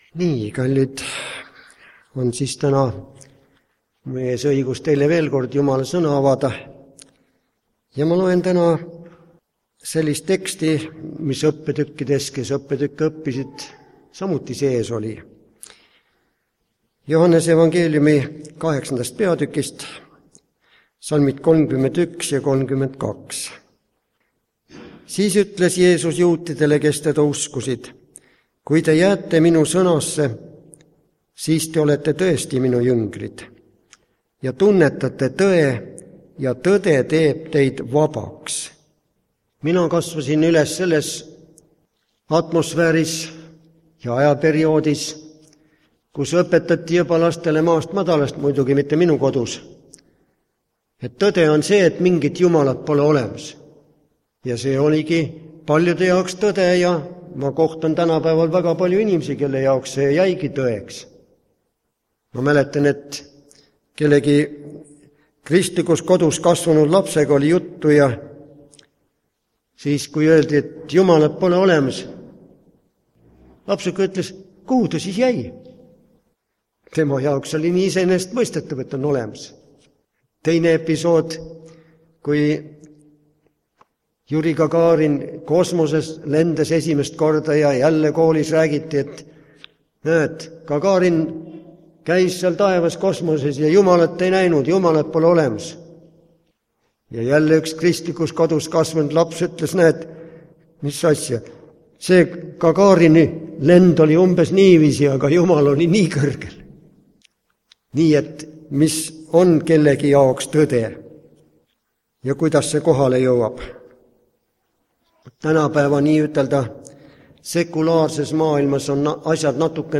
Tõde teeb teid vabaks (Haapsalus)
Jutlused